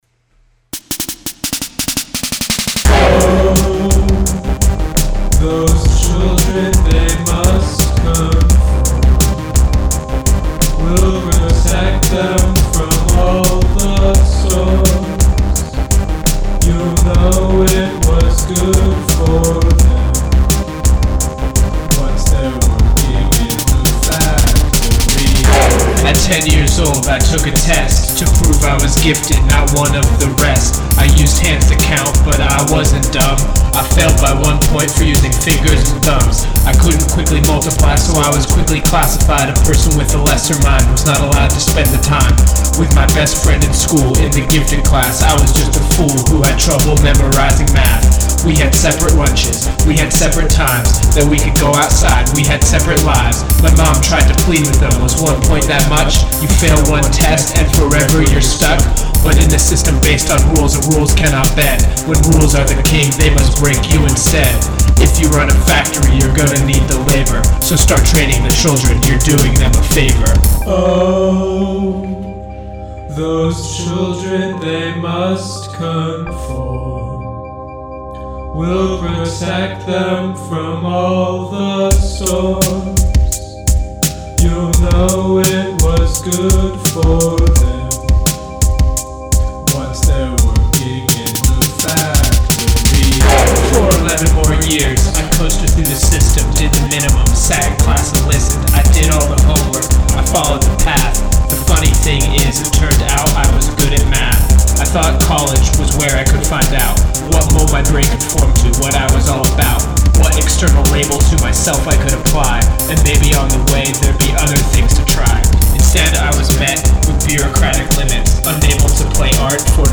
This is my first completed rap song. It's about the negative feelings I have towards mandatory schooling.
The beat has a slightly different structure from Part 1. The rap vocals are three layers each panned differently and pitch-bent to make my voice sound cooler. The master track has some reverb and a saturator acting as a limiter.